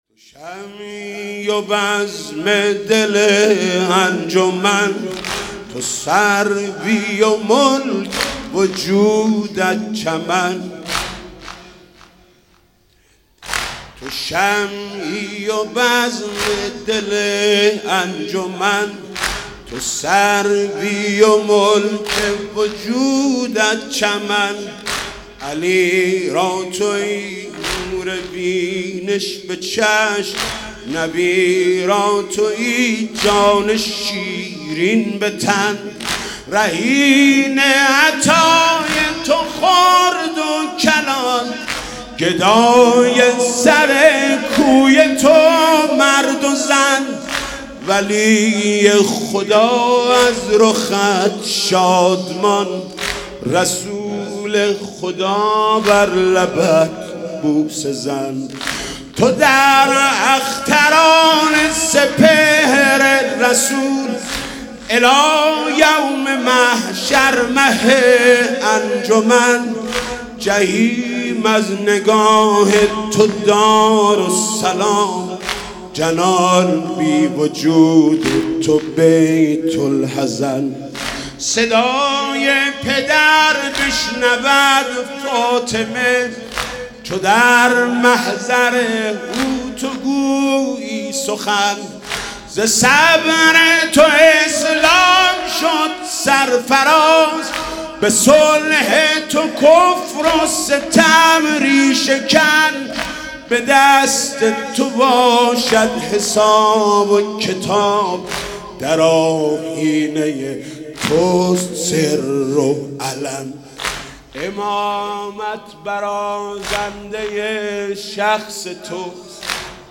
شب سوم رمضان 95
واحد، زمینه